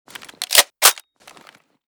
scar_unjam.ogg.bak